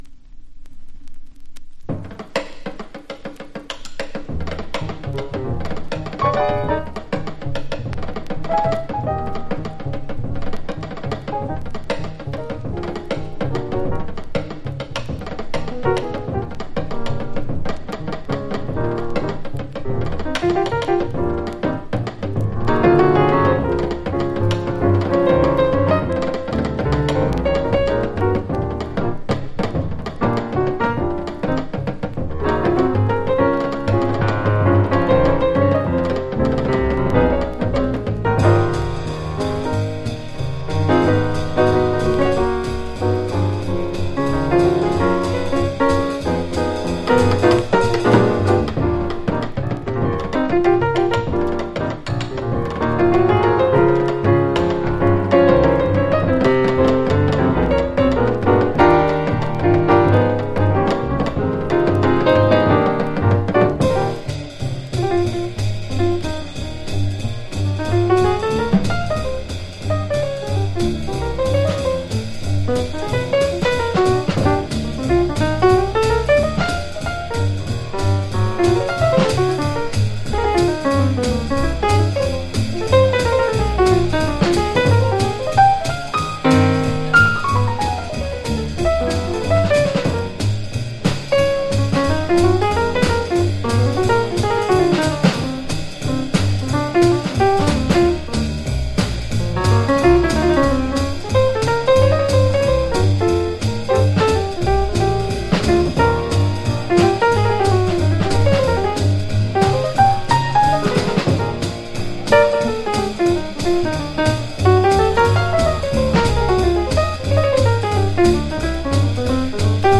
（小傷によりチリ、プチ音ある…